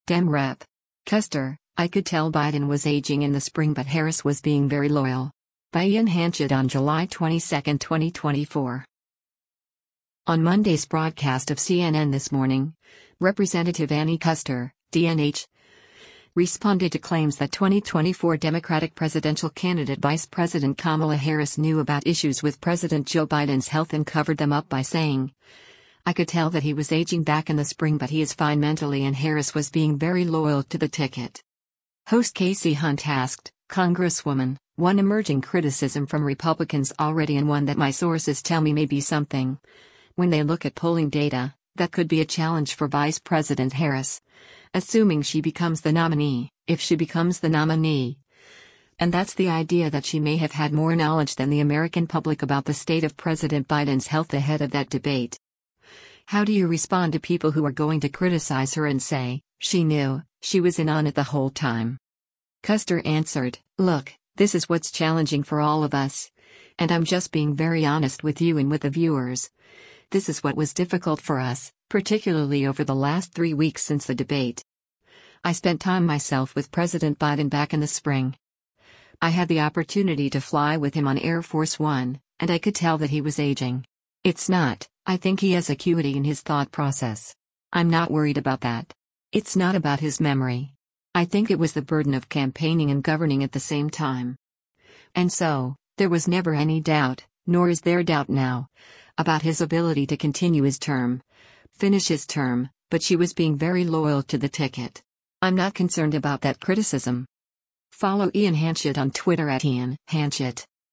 On Monday’s broadcast of “CNN This Morning,” Rep. Annie Kuster (D-NH) responded to claims that 2024 Democratic presidential candidate Vice President Kamala Harris knew about issues with President Joe Biden’s health and covered them up by saying, “I could tell that he was aging” “back in the spring” but he’s fine mentally and Harris “was being very loyal to the ticket.”